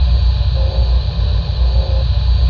game-source/ParoxysmII/sound/ambience/hdrone1.wav at master
hdrone1.wav